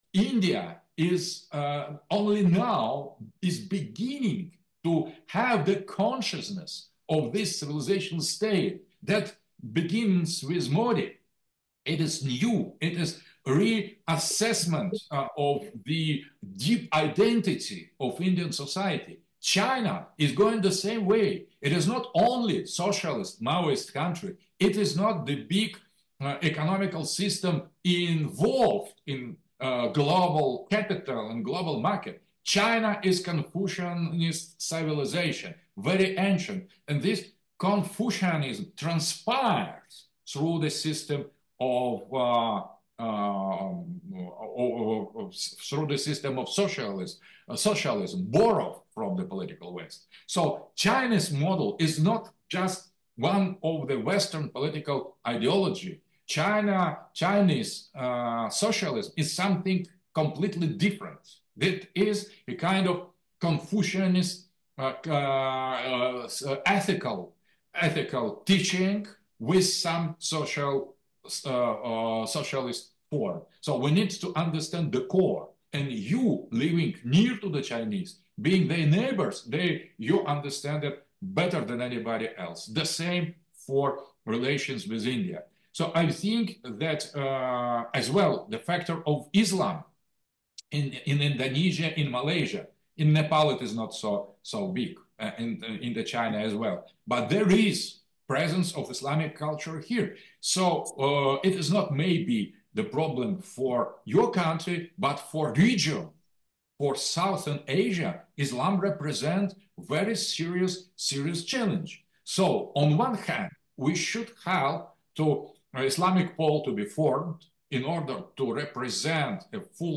Alexander Dugin's Kathmandu Conference speech 2
speech 2